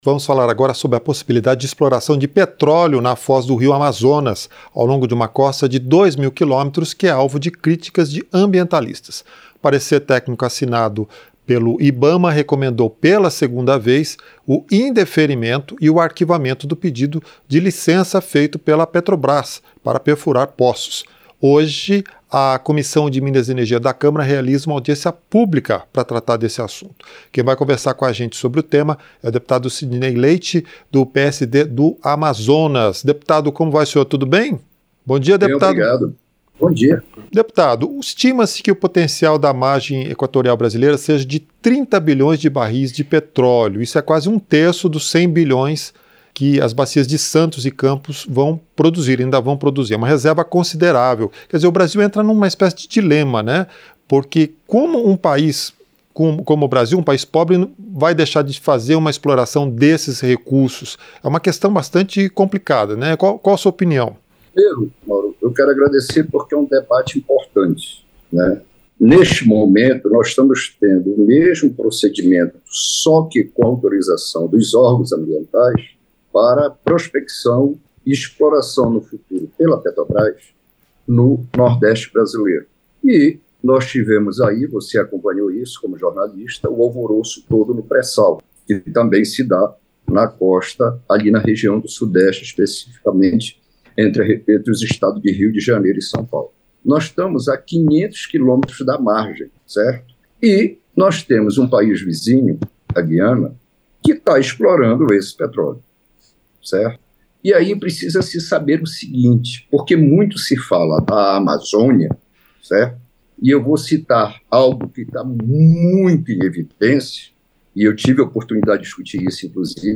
Entrevista - Dep. Sidney Leite (PSD-AM)